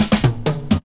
01DR.BREAK.wav